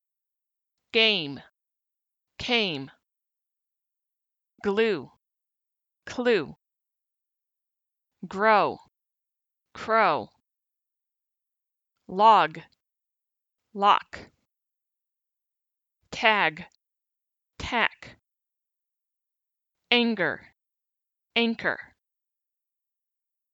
Home Pronunciation Checker Academy American English Sounds - /g/ voiced, velar, stop consonant
These are both velar, stop consonants. However, /g/ is a voiced consonant while /k/ is a voiceless consonant. When you pronounce /g/, your vocal cords should vibrate.
You can hear the difference between /g/ and /k/ in these words.
compare_g-k_words.mp3